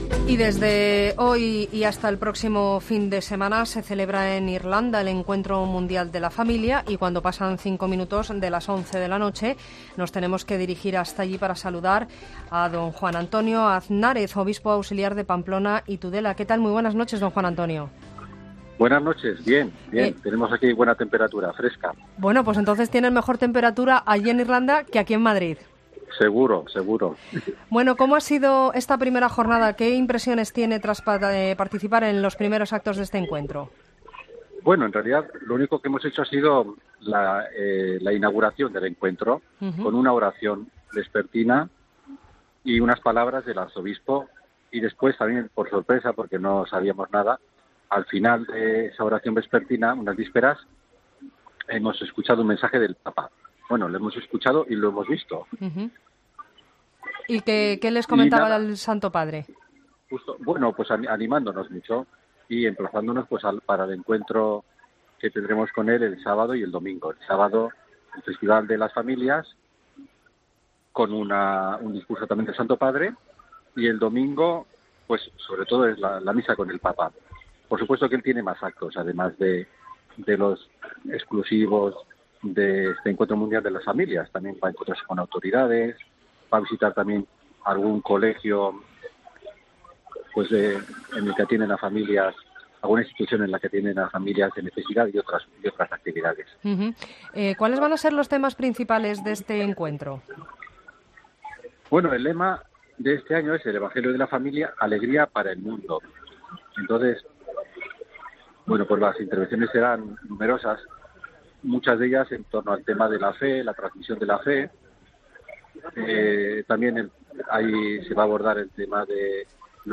Entrevista a Juan Antonio Azánrez
Juan Antonio Aznárez, el obispo auxiliar de Pamplona y Tudela se ha pasado por los micrófonos de 'La Linterna' para analizar el primer día del Encuentro Mundial de la Familia.